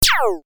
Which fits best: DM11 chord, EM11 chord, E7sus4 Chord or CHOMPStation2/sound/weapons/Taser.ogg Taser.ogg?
CHOMPStation2/sound/weapons/Taser.ogg Taser.ogg